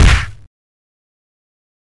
Batman Punch
Great batman and robin punch sound effect. Not the wham, but a great hit impact sound effect.